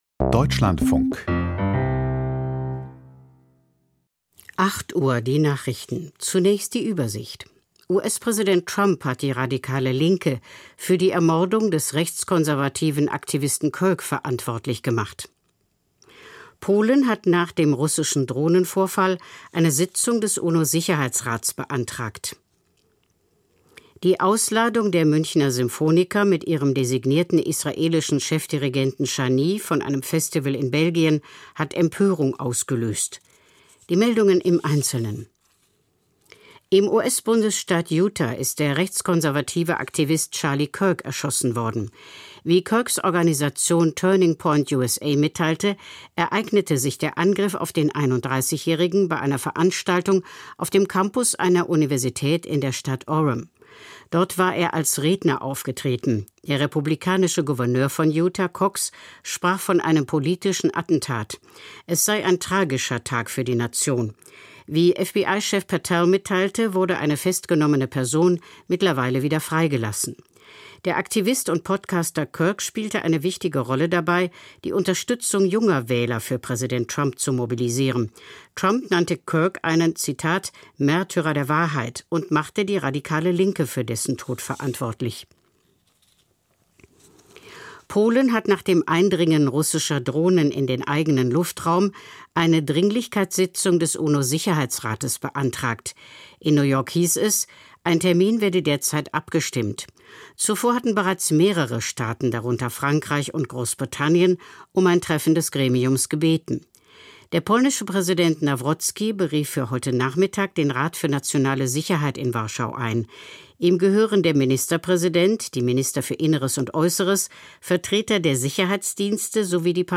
Die Nachrichten vom 11.09.2025, 08:00 Uhr
Aus der Deutschlandfunk-Nachrichtenredaktion.